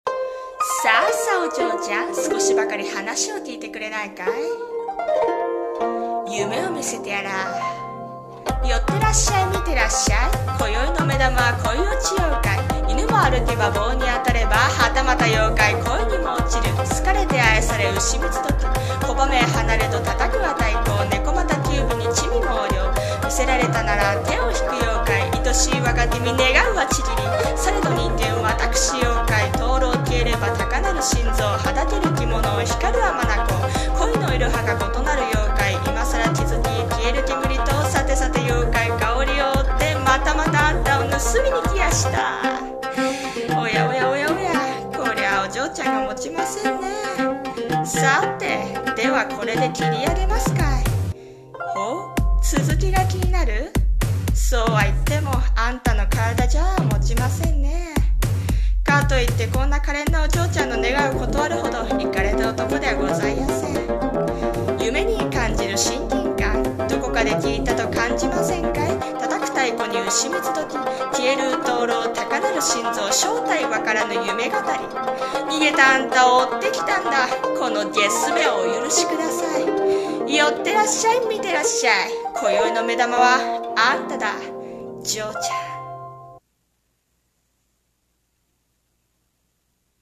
さんの投稿した曲一覧 を表示 【声劇台本】恋堕ち妖怪夢語り